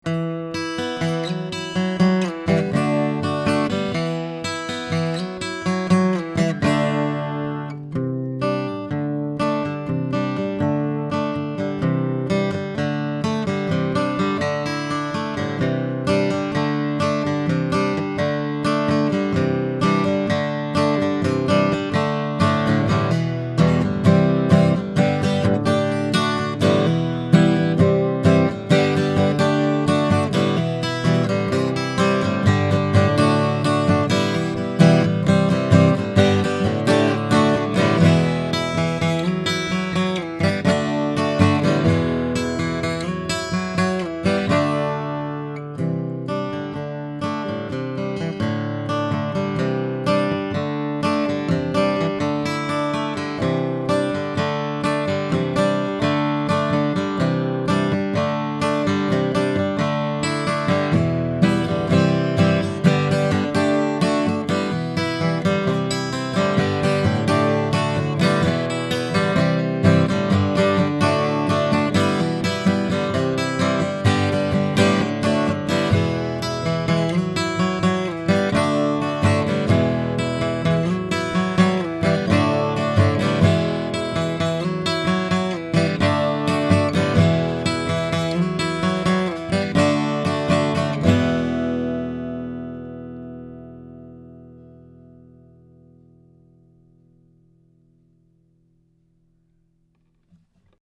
Here are some of my sound files of the BB4 equipped with an Audient Black Preamp -- just to show you the sound of the Black Series preamp, using a Blackspade Acoustics UM17 tube mic, and recorded into a Sony PCM D1 flash recorder, with no EQ or Compression: